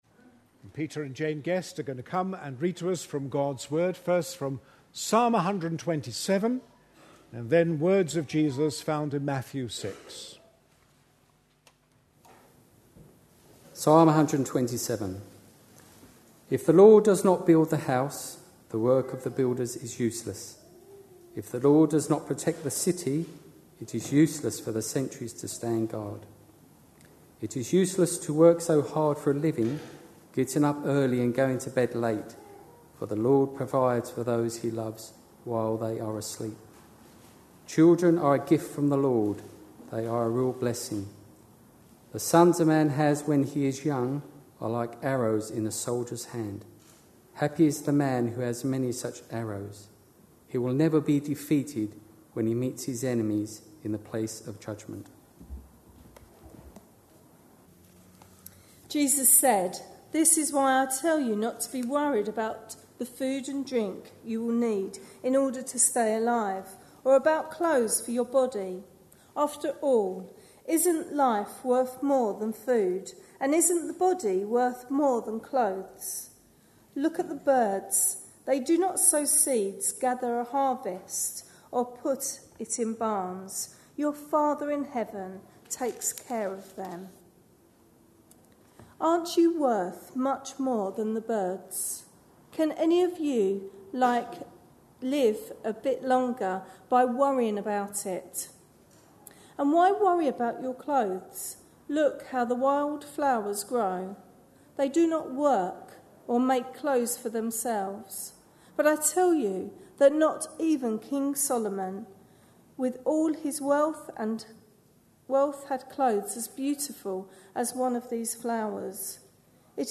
A sermon preached on 21st August, 2011, as part of our Psalms we Love series.